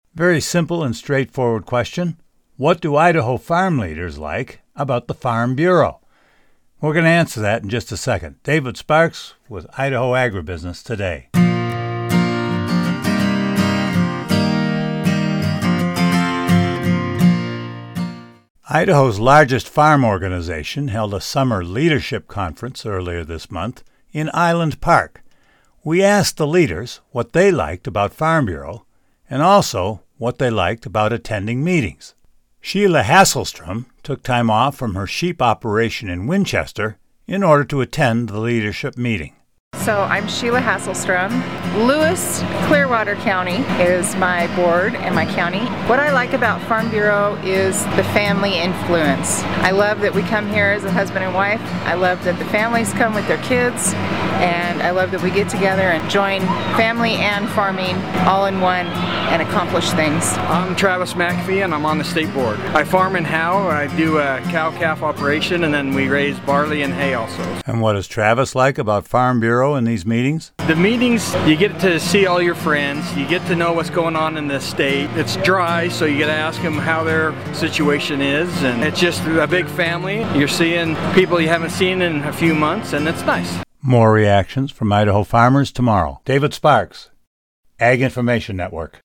Idaho’s largest farm organization held a summer leadership conference earlier this month in Island Park. We asked the leaders what they liked about Farm Bureau and also what they liked about attending meetings.